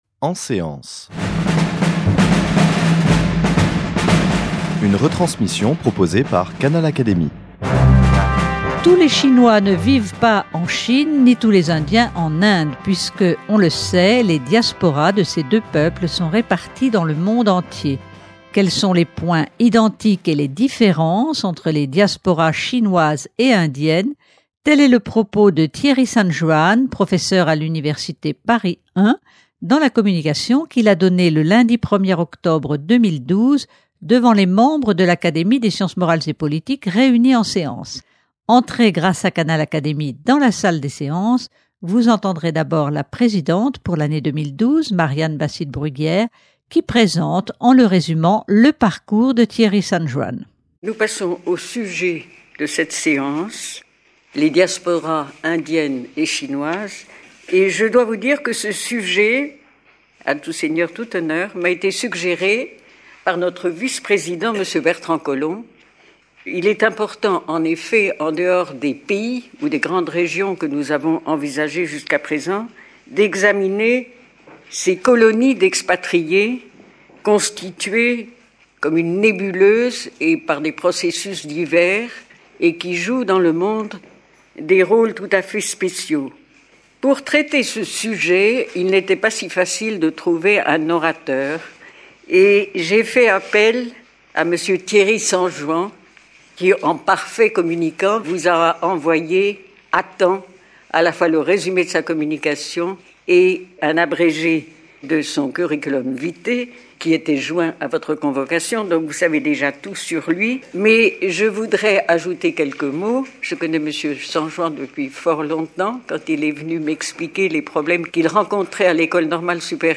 devant les membres de l’Académie des sciences morales et politiques réunis en séance.